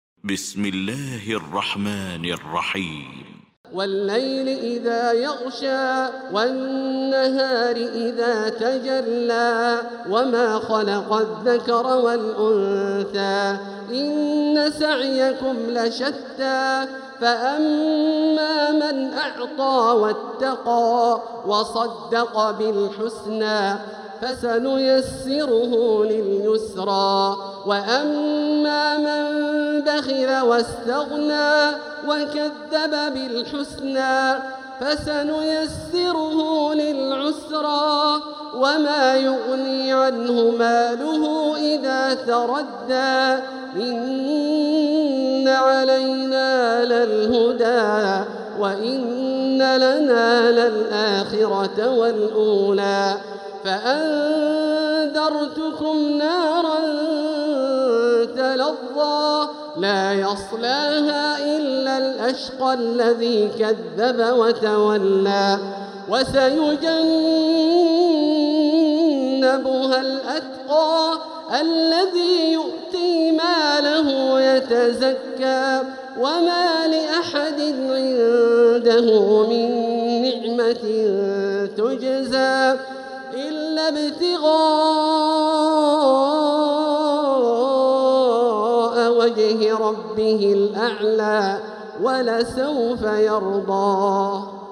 المكان: المسجد الحرام الشيخ: فضيلة الشيخ عبدالله الجهني فضيلة الشيخ عبدالله الجهني الليل